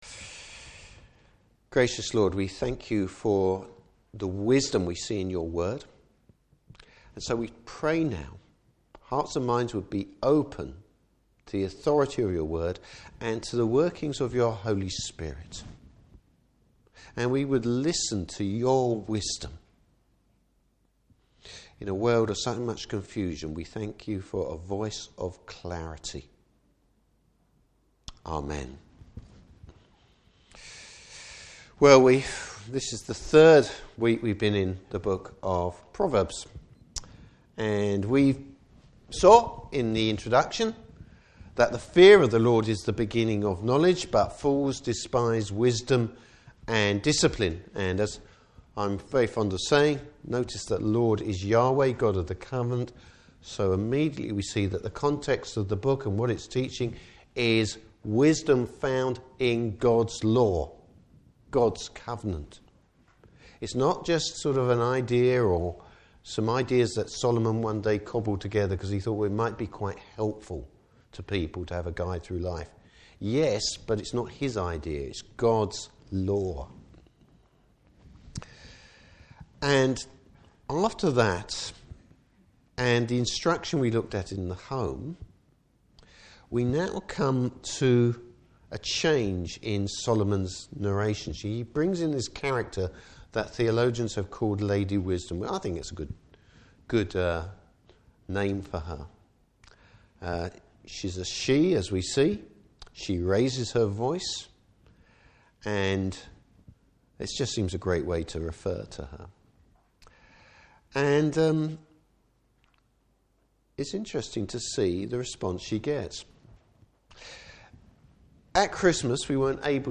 Service Type: Morning Service There are only two ways to live!